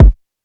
NEEN 2MOODZ-KIK.wav